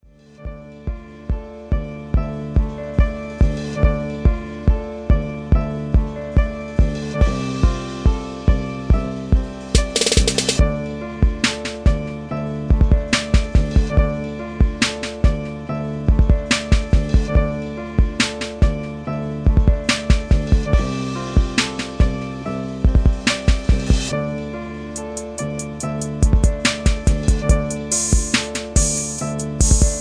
Smooth but gangsta!